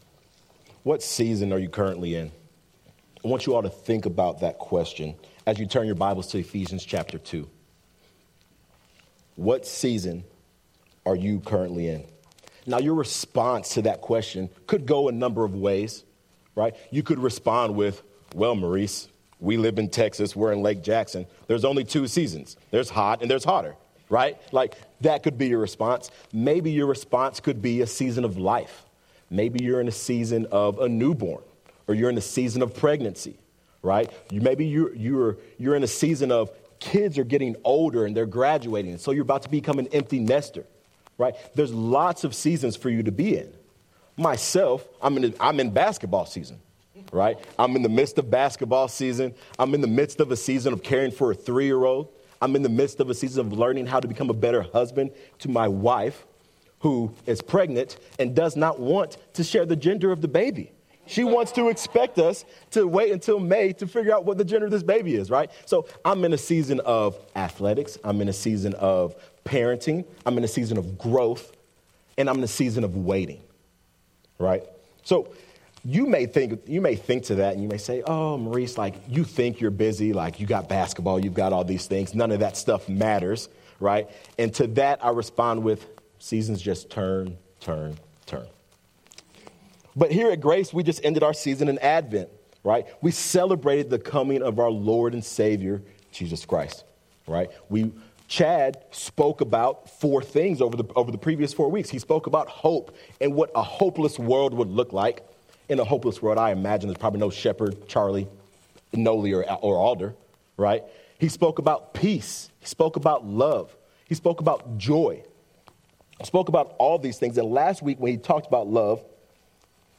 Sermon audio from Grace Bible Church in Clute, TX … continue reading 146 episodes # Religion # Bible # Audio # Teaching # Baptist # Preaching # GBC # Christianity # Sermons